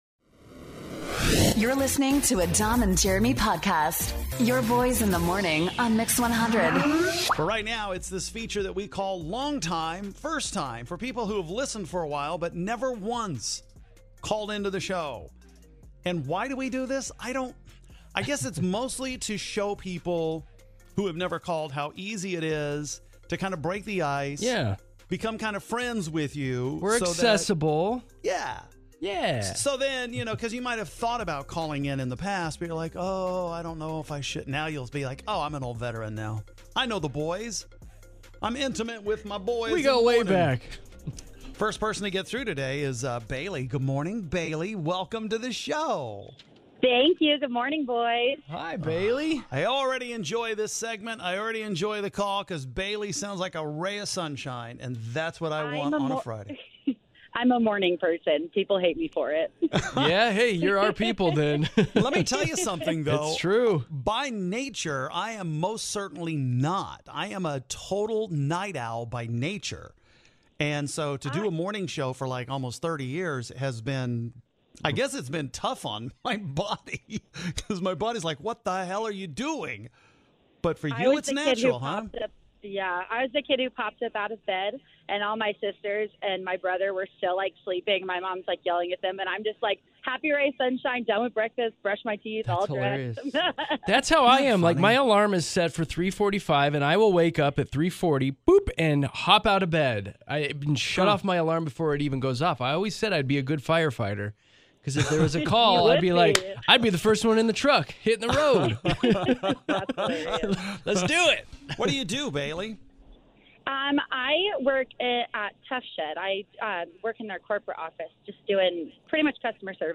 We talk to long time listeners for the first time ever after they call in.